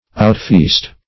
outfeast - definition of outfeast - synonyms, pronunciation, spelling from Free Dictionary
Outfeast \Out*feast"\ (out*f[=e]st"), v. t.